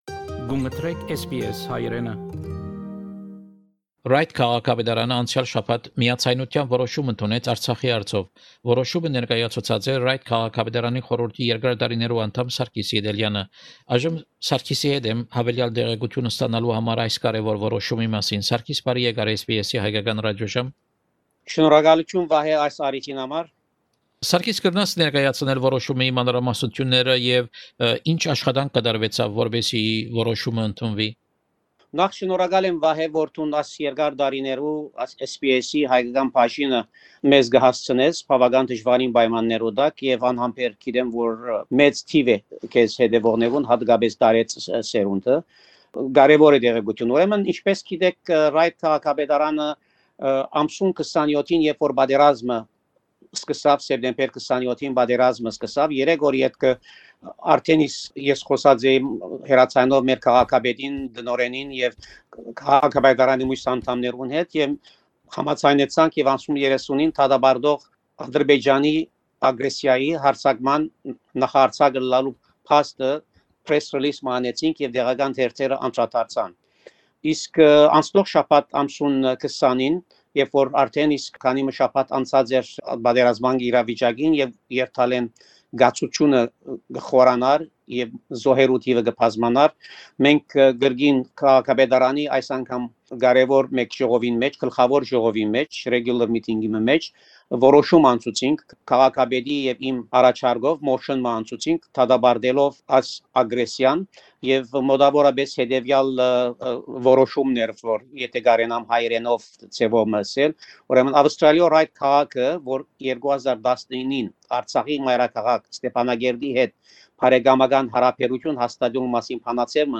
Հարցազրոյց Ռայտ քաղաքապետարանի խորհուրդի անդամ Պրն Սարգիս Ետելեանի հետ: Հարցազրոյցի գլխաւոր նիւթերն են՝ Ռայտ քաղաքապետարանի կողմէ Ատրպէյճանը և Թուրքիան դատապարտող որոշում, աւստրալիական մամուլի անդրադարձը Արցախեան պատերազմին, Նիու Սաութ Ուելս Նահանգի Ներկայացուցչական Պալատի կողմէ Արցախի Անկախութեան ճանաչումը և Սիտնիի քայլերթը նուիրուած Արցախի: